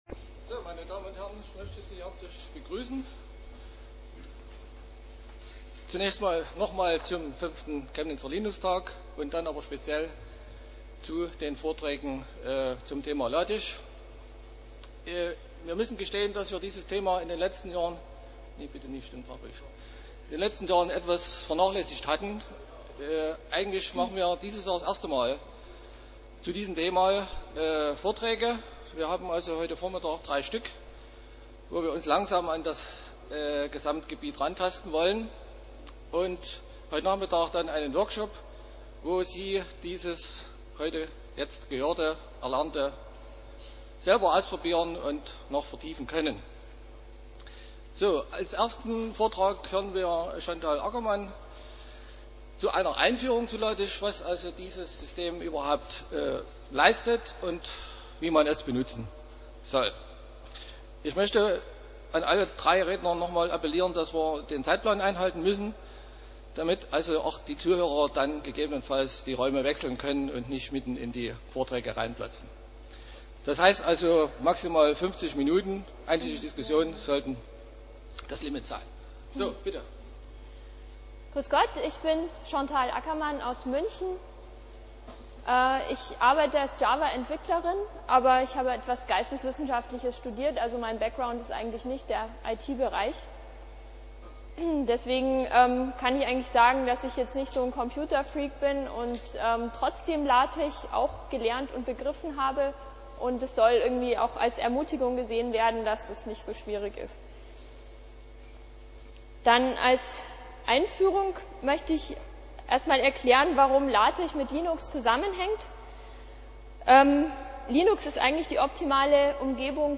LaTeX für Studium und Büro Samstag, 10:00 Uhr im Raum V1
Vortragsmittschnitt